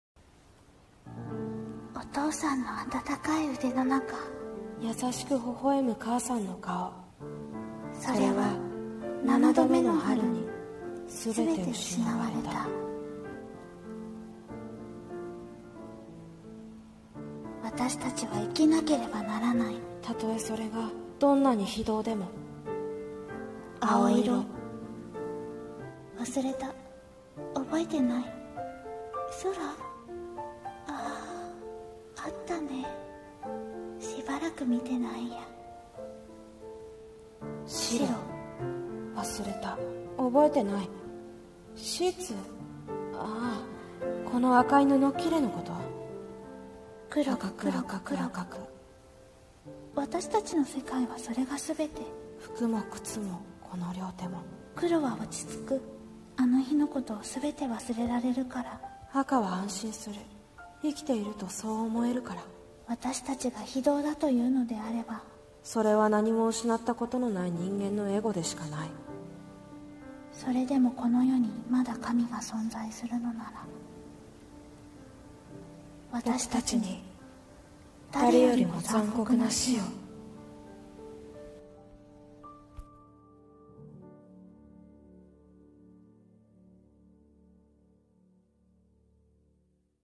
【声劇台本】赤と黒【掛け合い】コラボ / 姉×弟